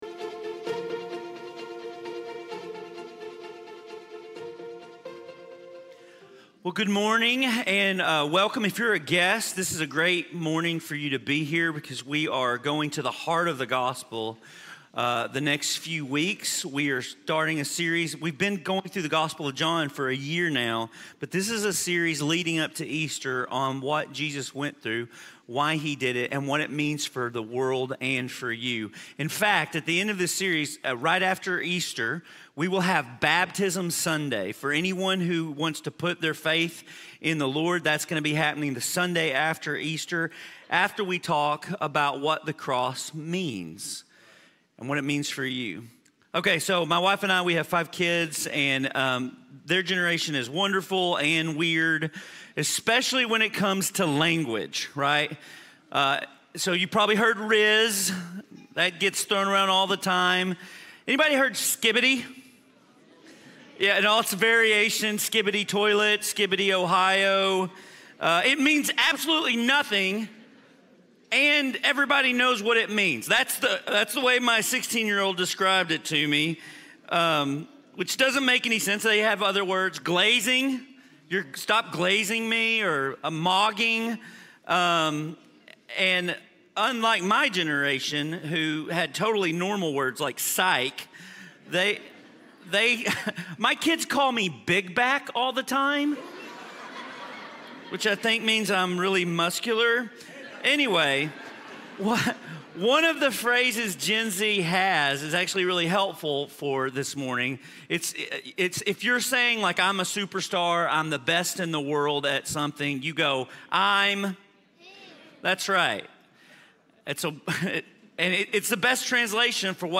Sermon-9-March-2025.mp3